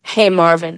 synthetic-wakewords
ovos-tts-plugin-deepponies_Billie Eilish_en.wav